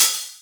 Index of /90_sSampleCDs/Club_Techno/Percussion/Hi Hat
Hat_O_05.wav